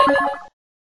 missile-get.mp3